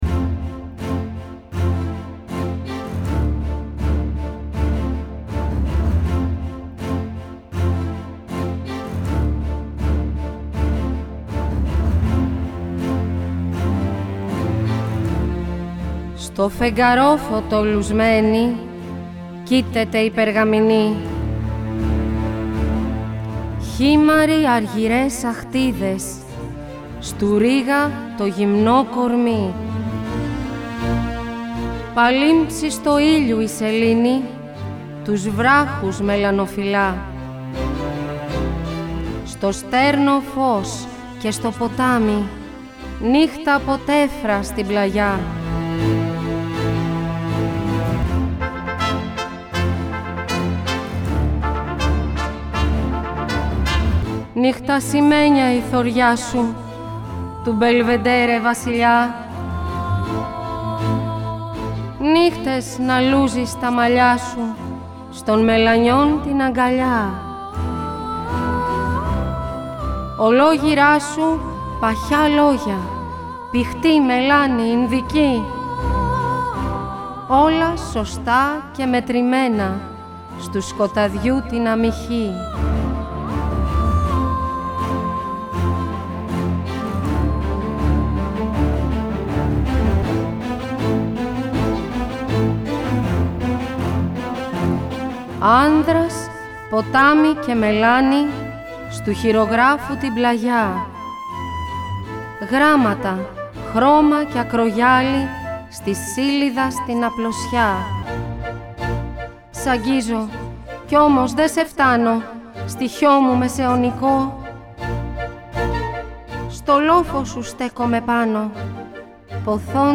Το μουσικό έργο Μελανοχτυπημένη αποτελείται απο 7 θέματα τα οποία δημιουργήθηκαν ως μουσική υπόκρουση για την απαγγελία των έμμετρων ποιημάτων του ομότιτλου μυθιστορήματος της Αρχοντούλας Αλεξανδροπούλου.
ΑΠΑΓΓΕΛΙΕΣ